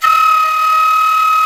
Index of /90_sSampleCDs/INIS - Opium/Partition H/DIZU FLUTE
DIZI02D#4.wav